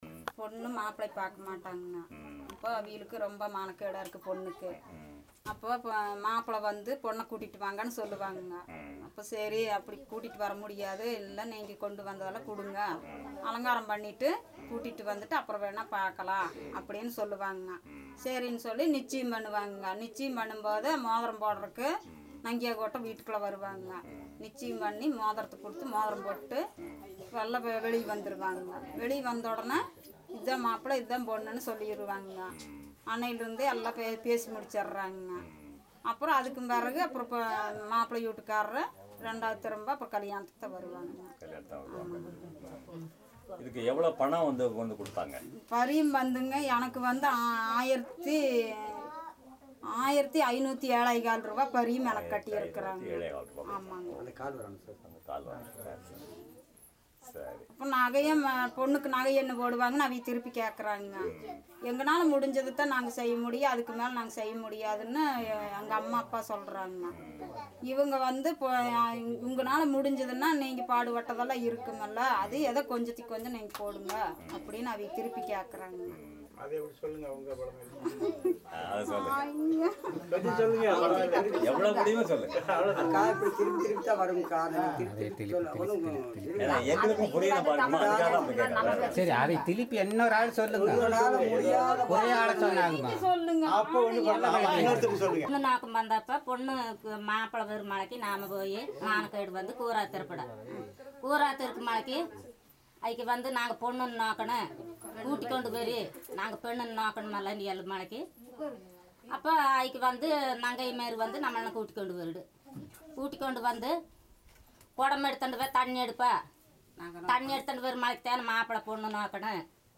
Conversation about wedding ceremonies